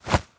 foley_cloth_light_fast_movement_03.wav